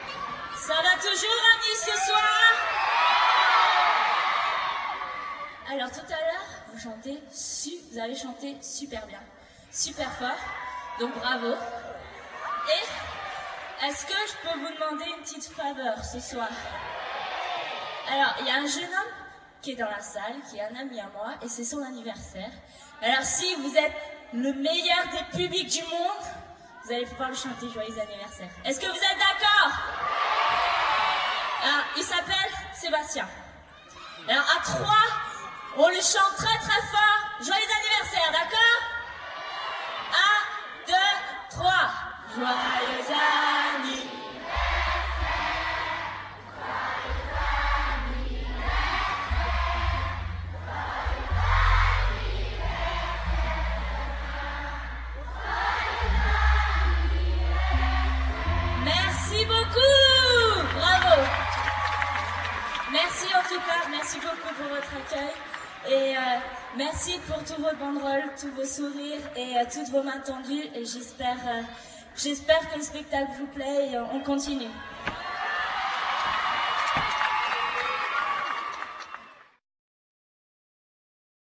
Concert de Nice